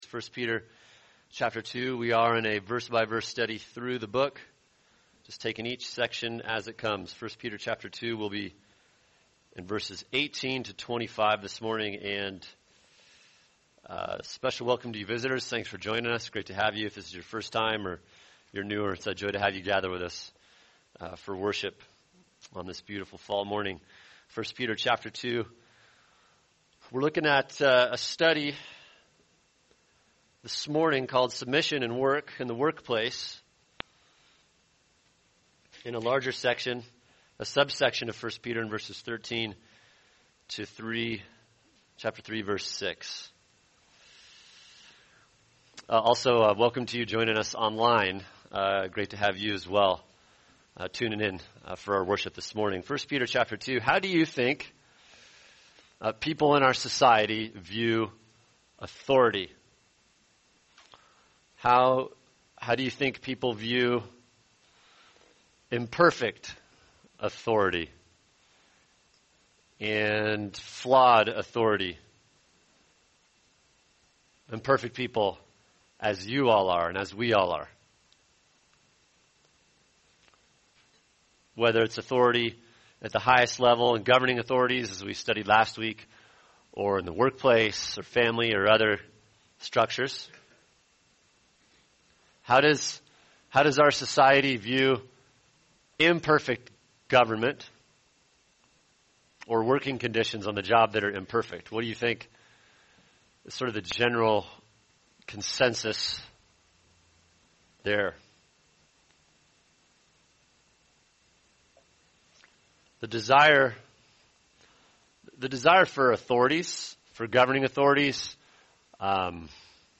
[sermon] 1 Peter 2:18-25 Submission and Work – Part 1 | Cornerstone Church - Jackson Hole